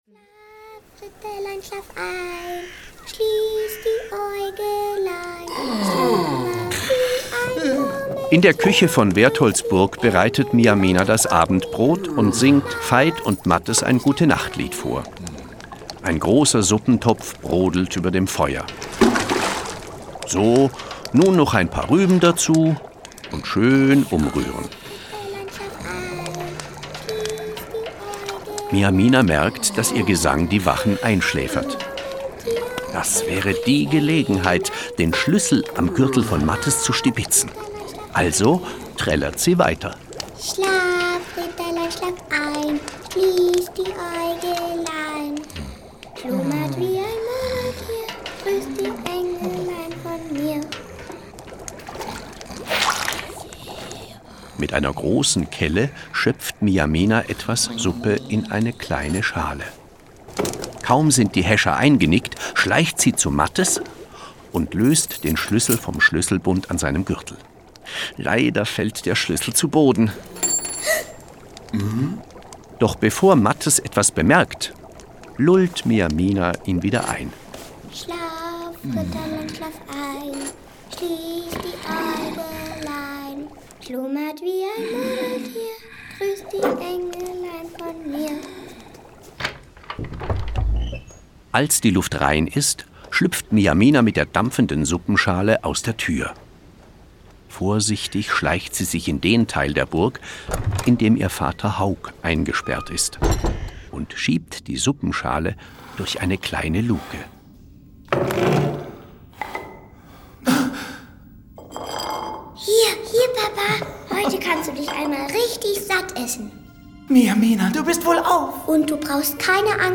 Original-Hörspiele zur ZDF-Serie Folge 11
Die Original-Hörspiele mit den Stimmen, der Atmosphäre und der Musik der ZDF-Serie lassen das Mittelalter in abwechslungsreichen Szenen wieder aufleben.